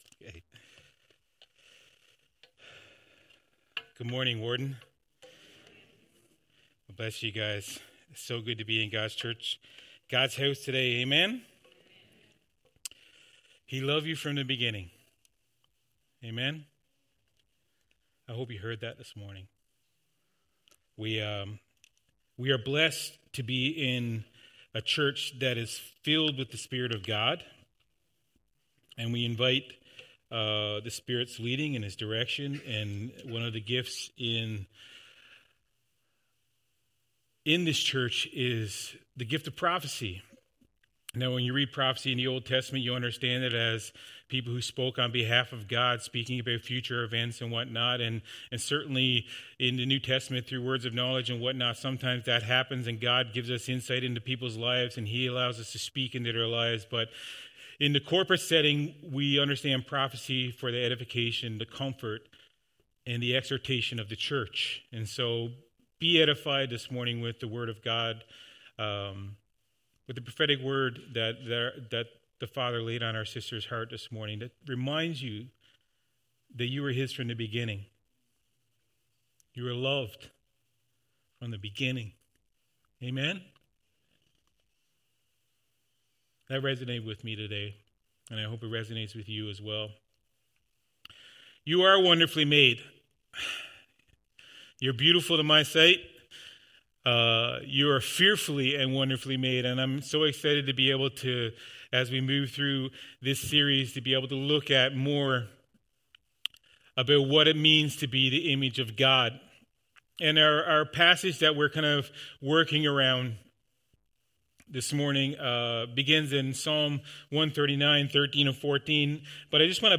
Sermons | Warden Full Gospel Assembly